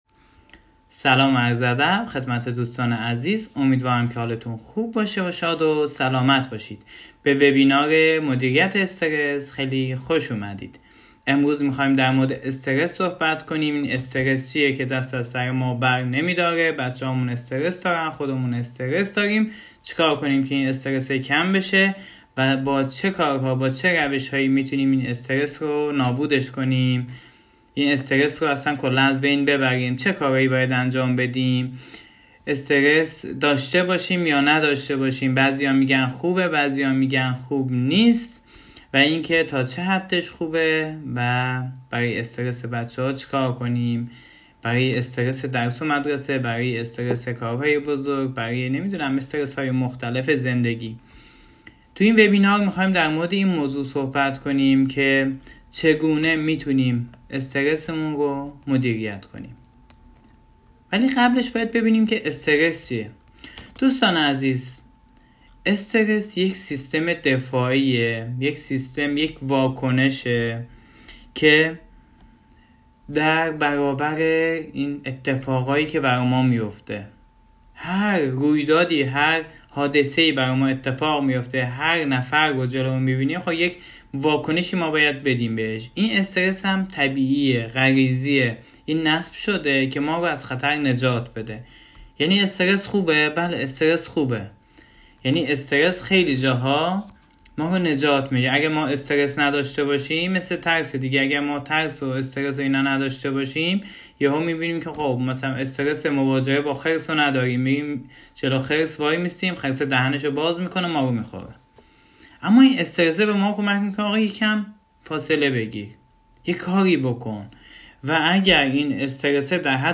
وبینار صوتی مدیریت استرس همراه با کد تخفیف 7 درصدی